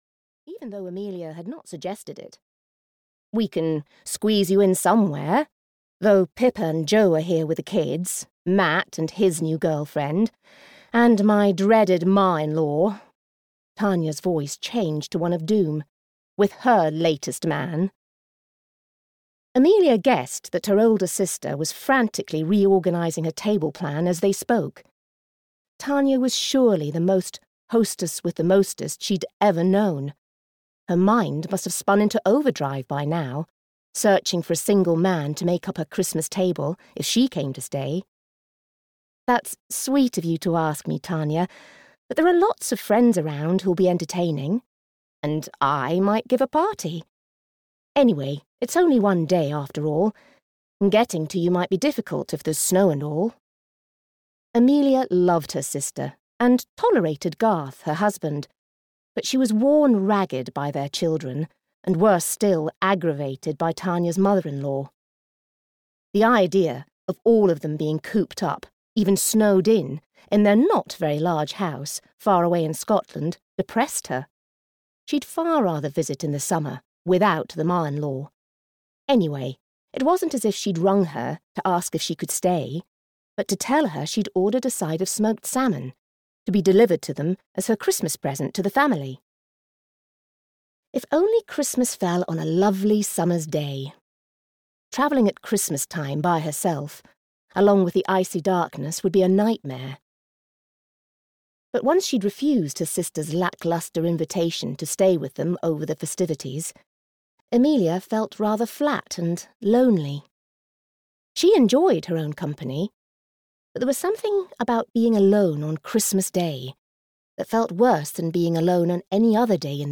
The Christmas Menagerie (EN) audiokniha
Ukázka z knihy